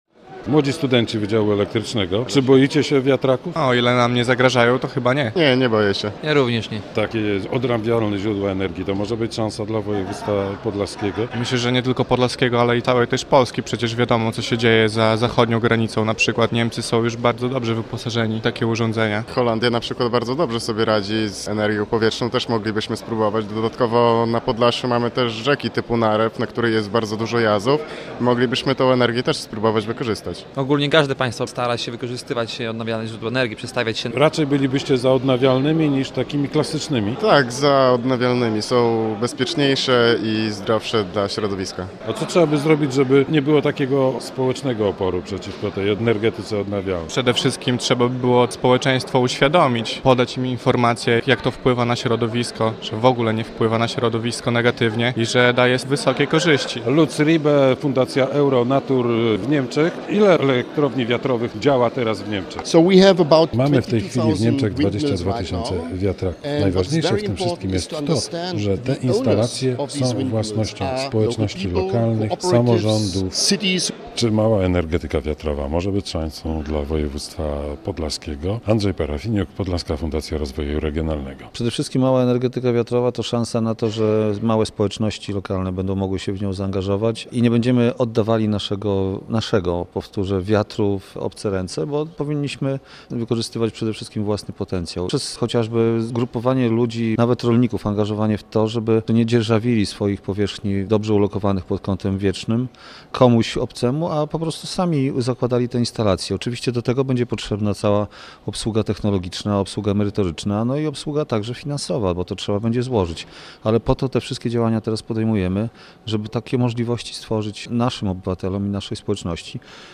relacja
Odbyła się tu konferencja pod hasłem "Energetyka odnawialna szansą rozwoju województwa podlaskiego".